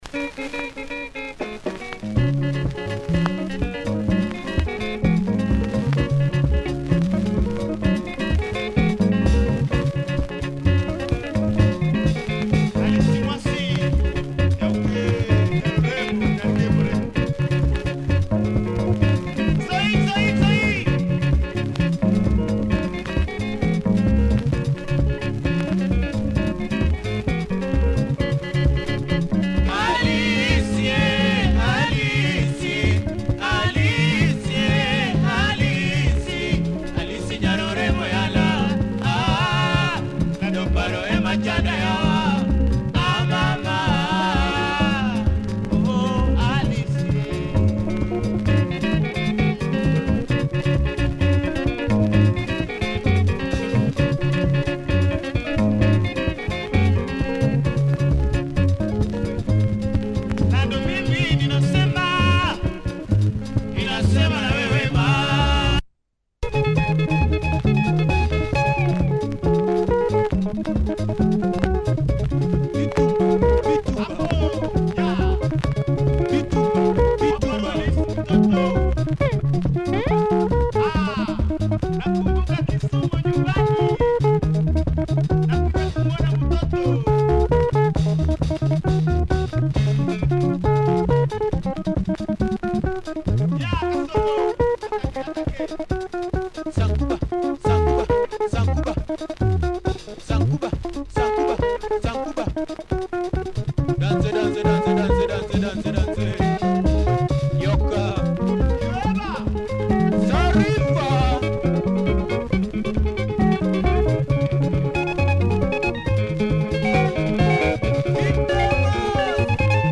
Fantastic groove in both sides of the slice